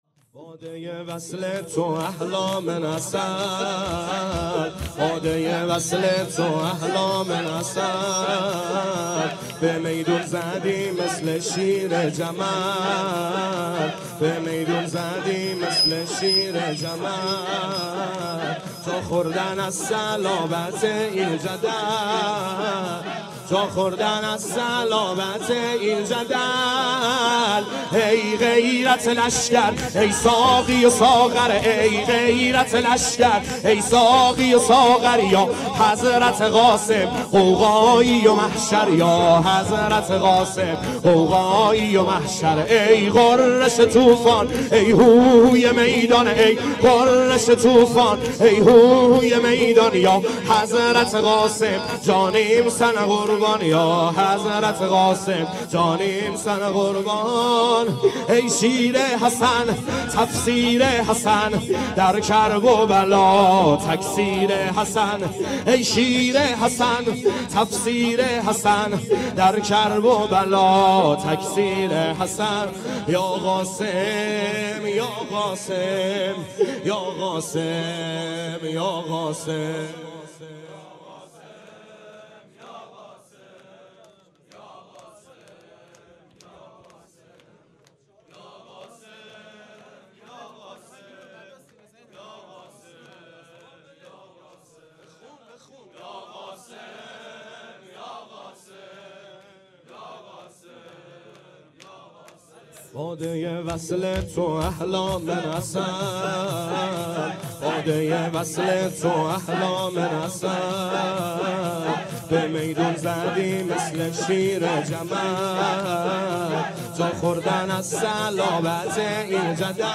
شور
محرم 97 - شب ششم